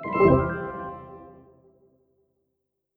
alert_window.wav